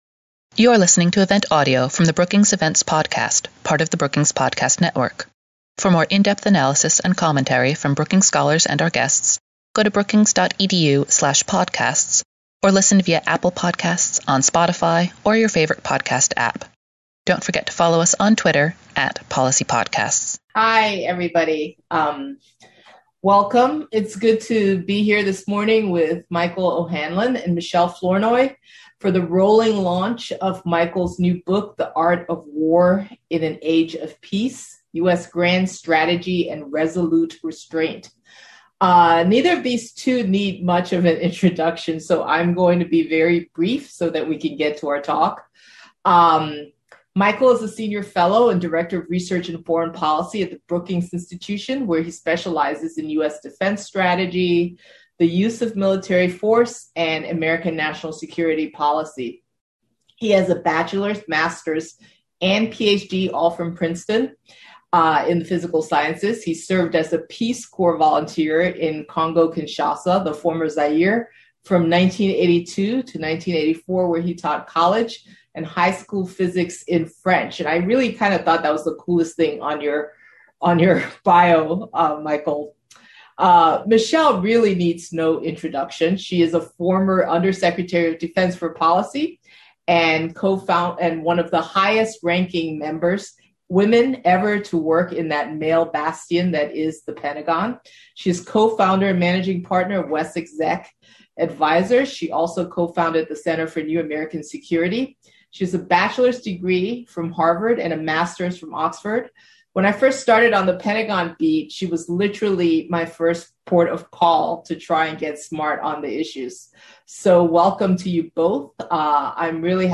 Questions from the audience followed.